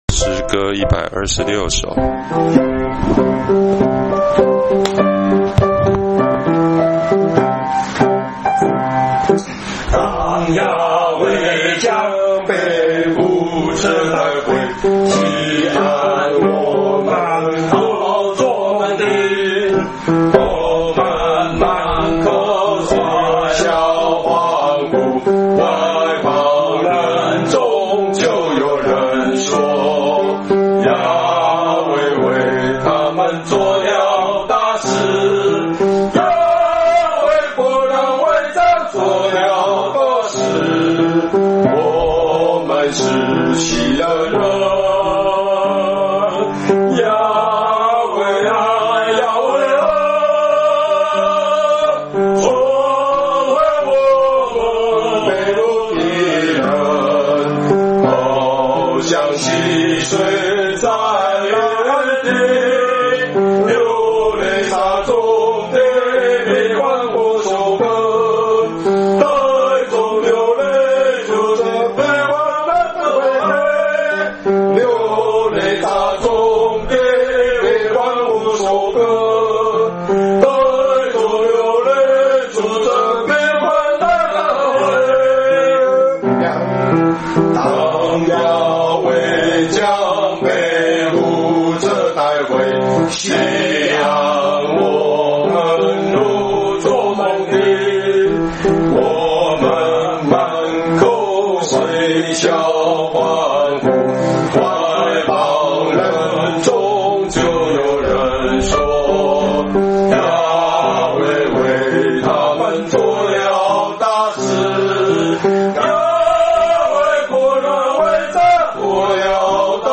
詩頌